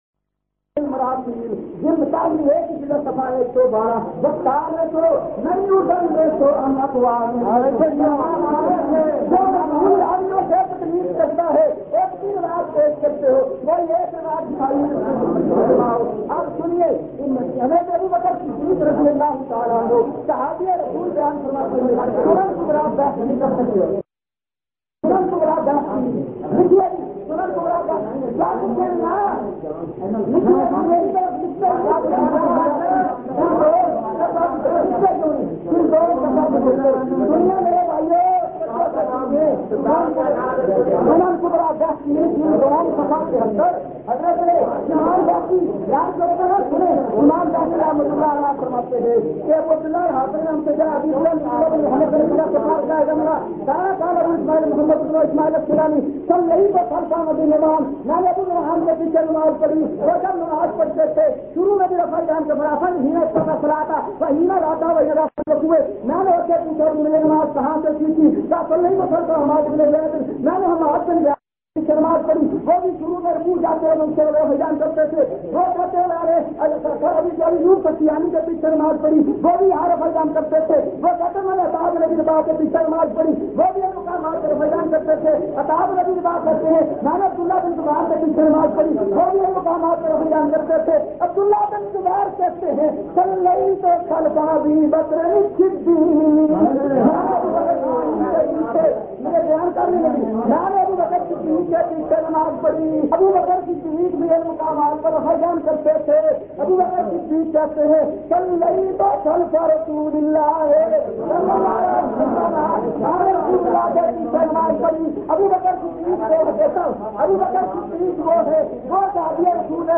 Audio Munazara